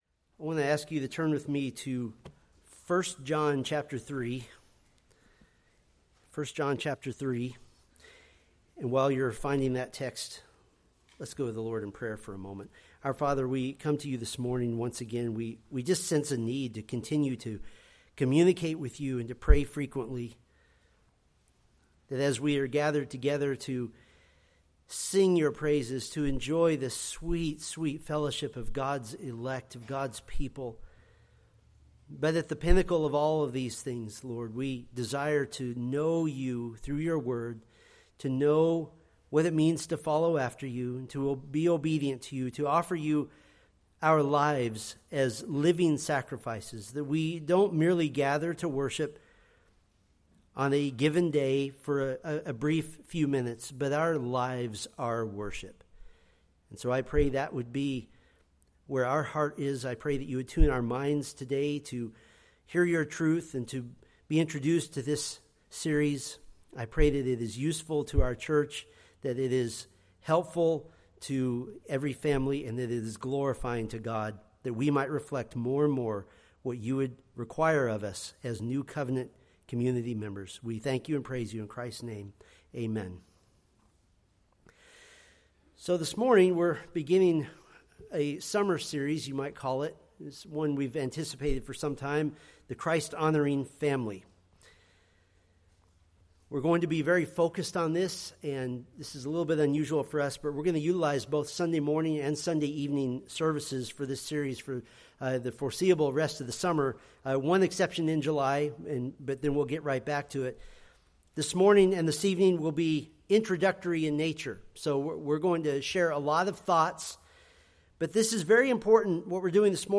Preached June 29, 2025 from Selected Scriptures